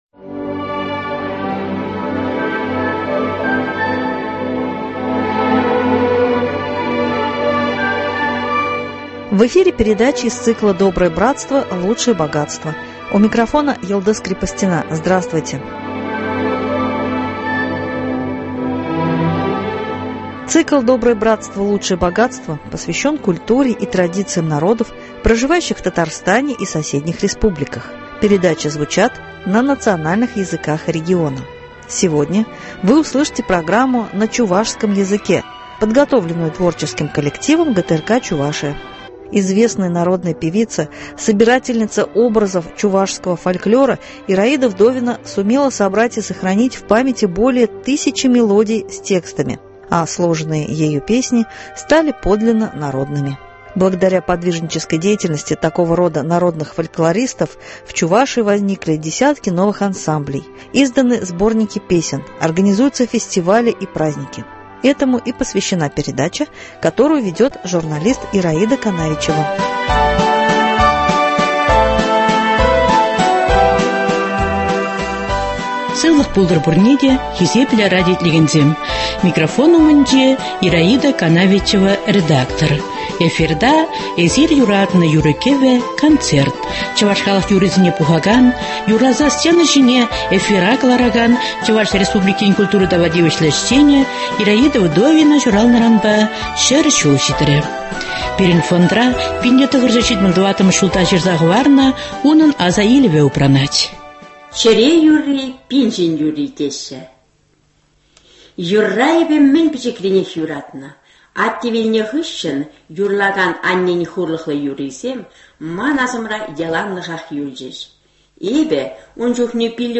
Цикл посвящен культуре и традициям народов, проживающих в Татарстане и соседних республиках, передачи звучат на национальных языках региона . Сегодня вы услышите передачу на чувашском языке , подготовленную творческим коллективом ГТРК «Чувашия».